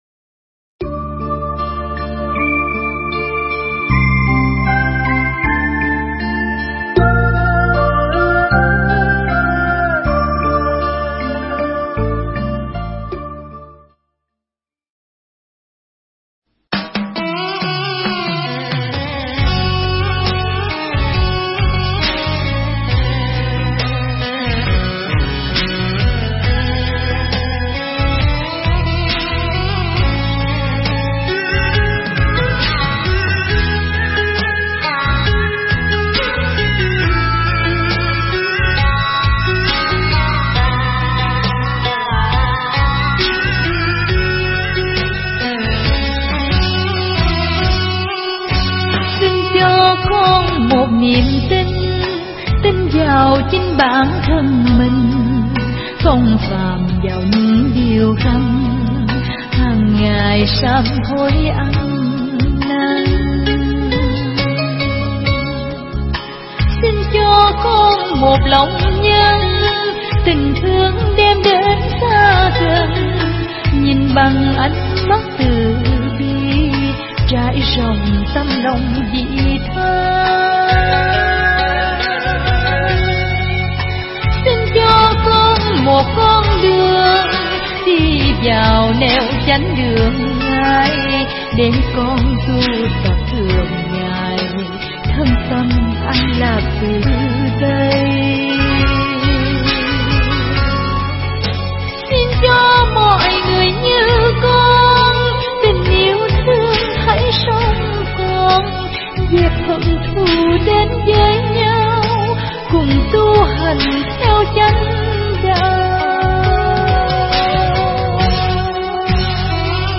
Nghe Mp3 thuyết pháp Thắp Sáng Niềm Tin Kỳ 12